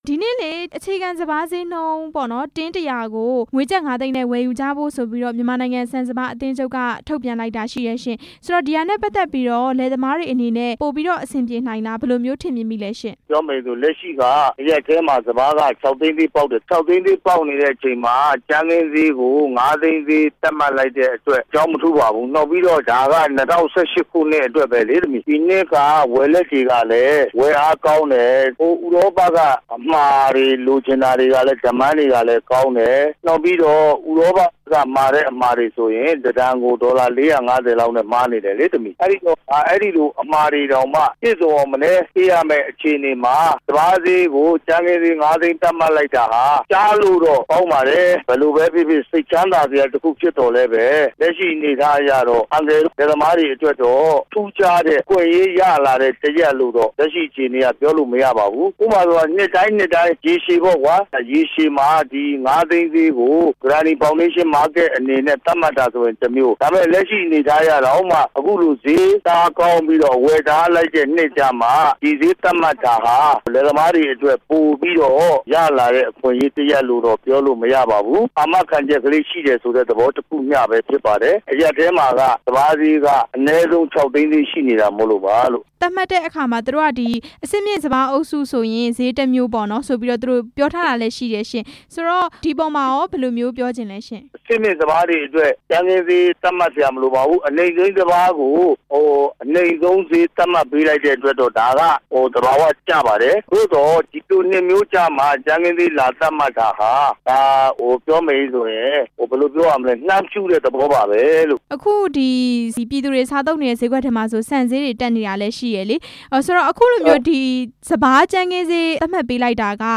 စပါးတင်း ၁၀၀ ကို ကျပ် ၅သိန်း သတ်မှတ်မှု မေးမြန်းချက်